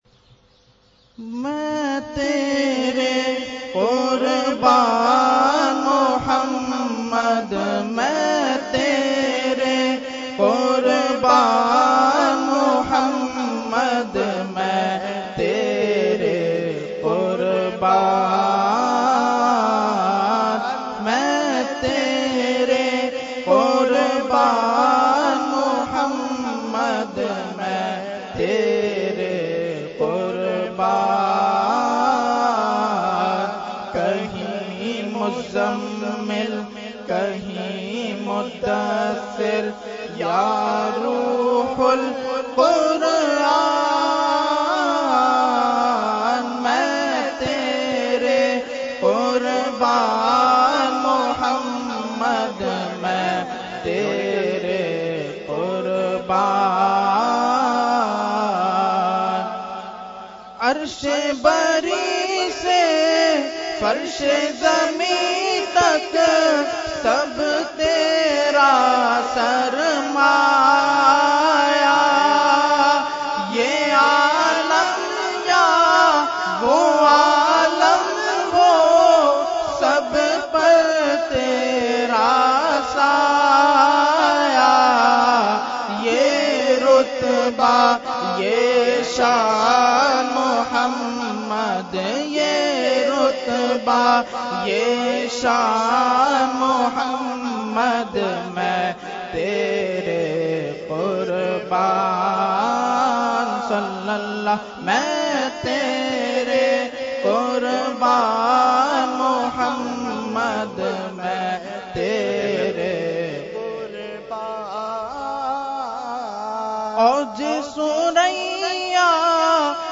Category : Naat | Language : UrduEvent : Urs Qutbe Rabbani 2018